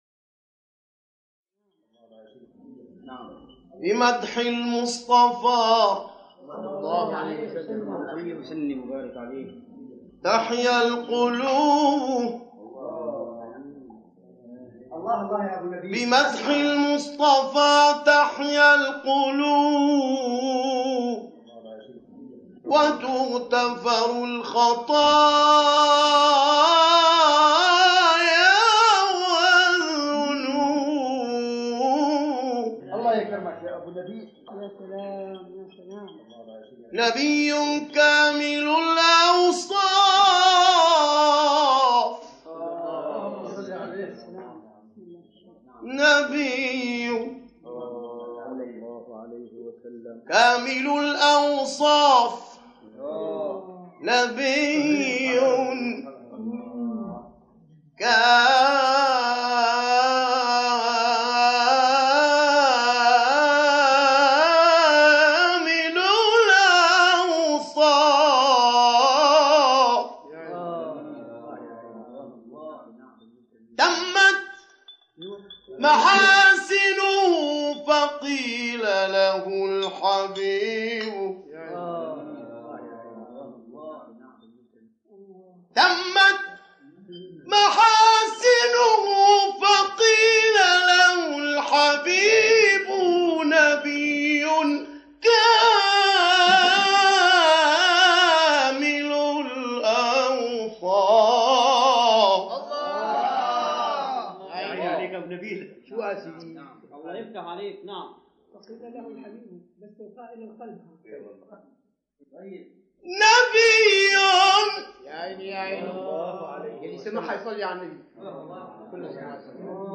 ابتهال نادر
در جلسه‌ای خصوصی در شهر دوما (شهری در ریف دمشق) کشور سوریه